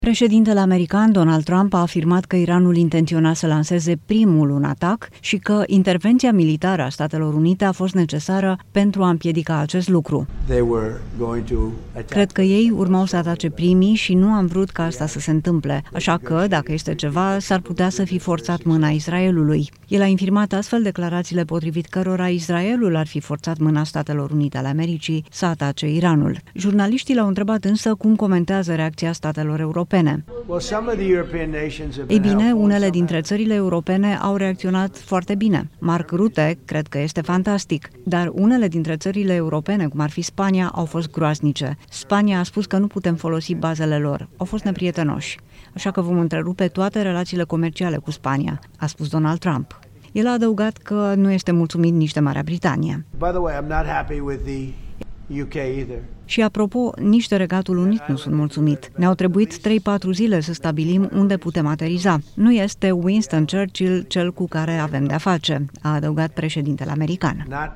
Noi declarații ale președintelui american, Donald Trump. Acesta a criticat anumite state europene – printre care Spania și Marea Britanie – pentru felul în care au reacționat în cazul conflictului din Iran. Trump chiar a anunțat că va întrerupe relațiile comerciale cu Spania, pentru că nu a fost de acord să fie folosite bazele sale militare.
Jurnaliștii l-au întrebat, însă, cum comentează reacția statelor europene.